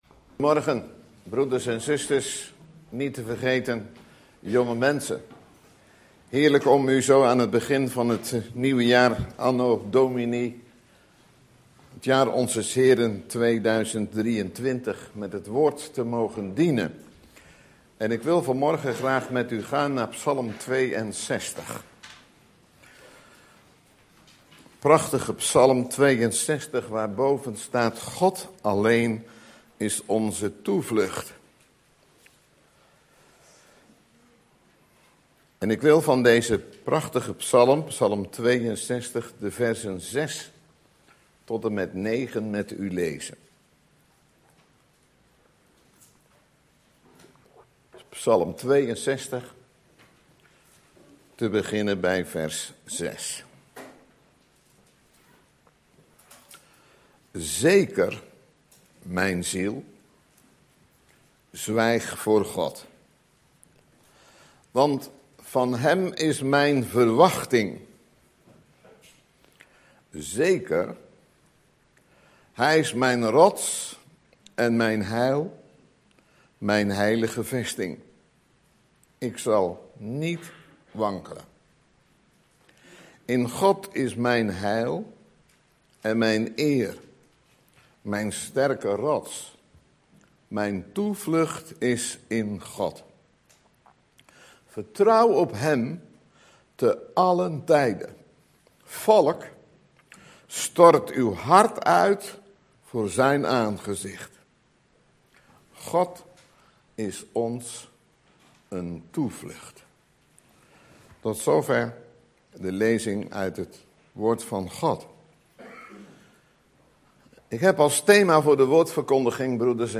In de preek aangehaalde bijbelteksten (Statenvertaling)Psalmen 621 Een psalm van David, voor den opperzangmeester, over Jeduthun.